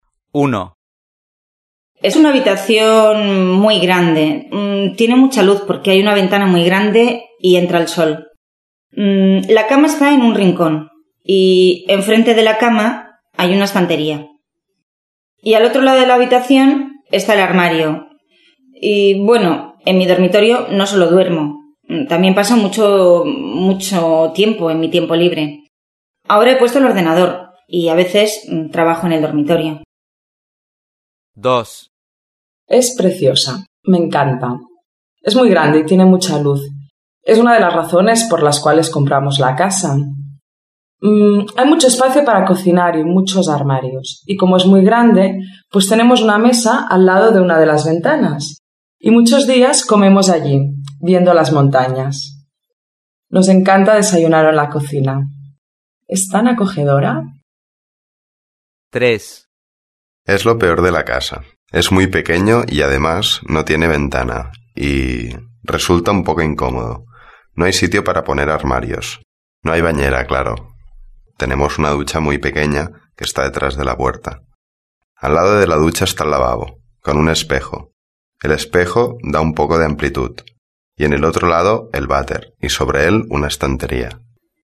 1. Va a escuchar a tres personas describiendo habitaciones.